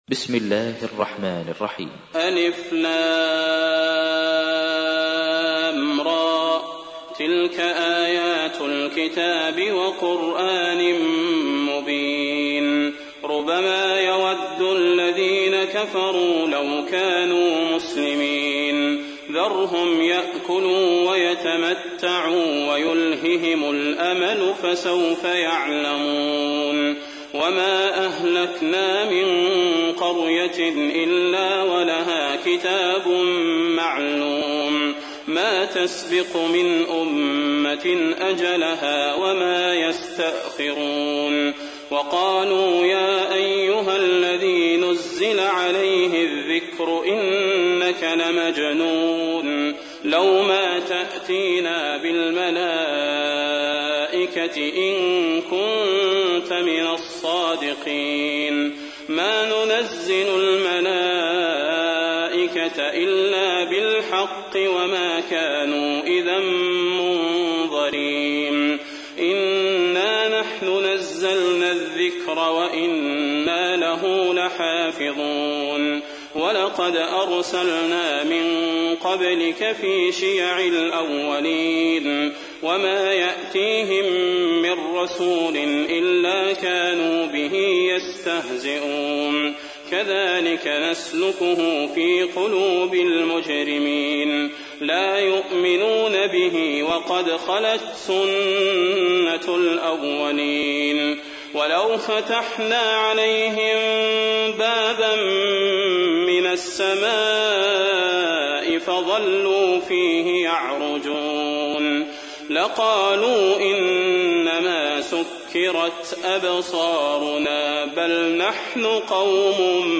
تحميل : 15. سورة الحجر / القارئ صلاح البدير / القرآن الكريم / موقع يا حسين